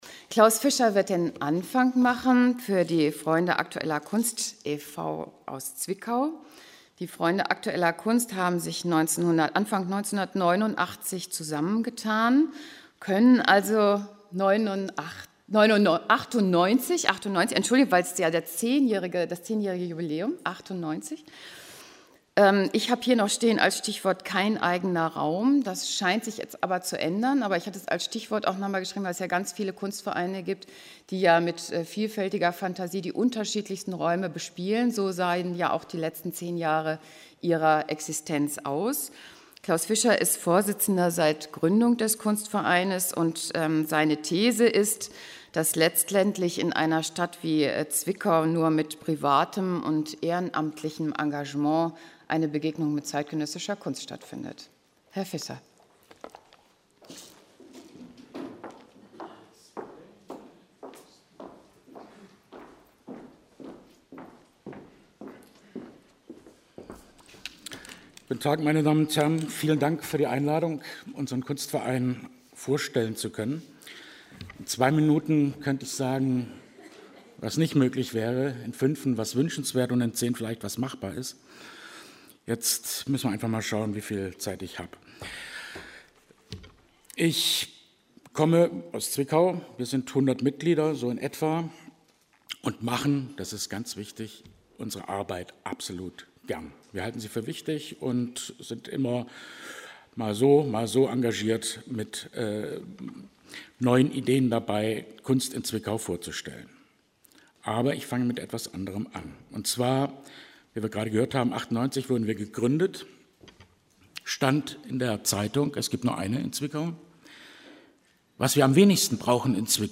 Akadamie der Künste Berlin Vortrag